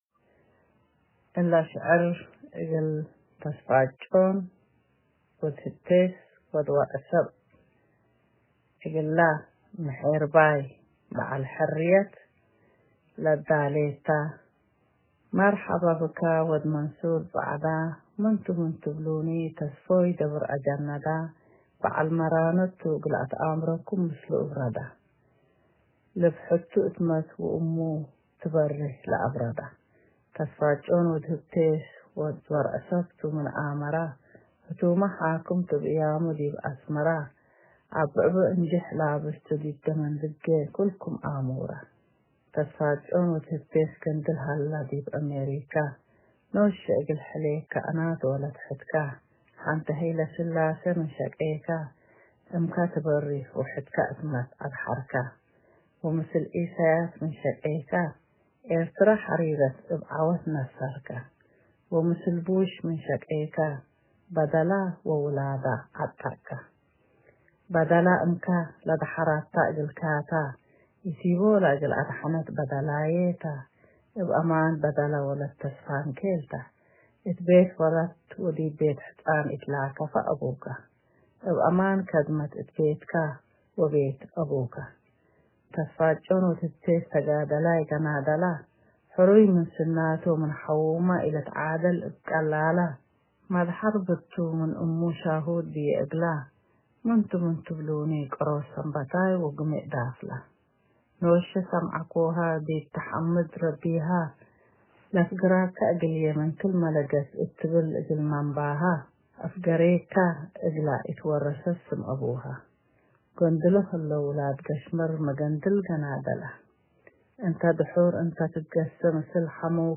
Your pleasant and appealing tone gave your poem the perfect flow.